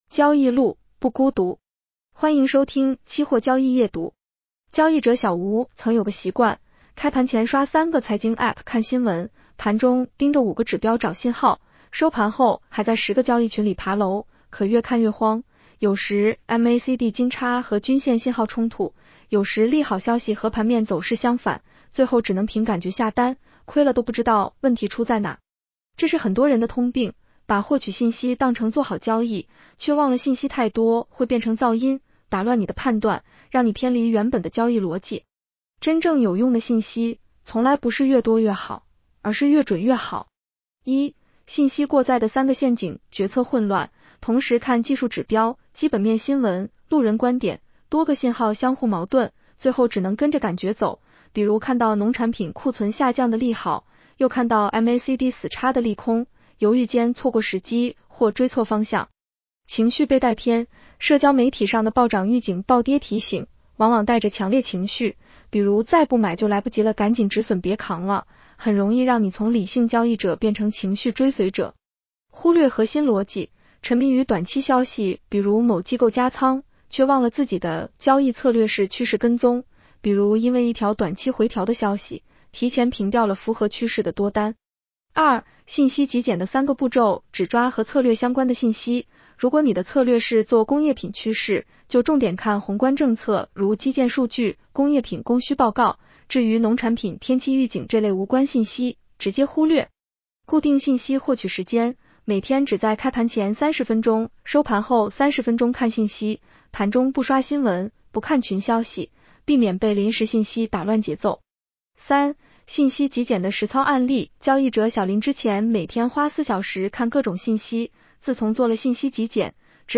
女声普通话版 下载mp3 交易路，不孤独。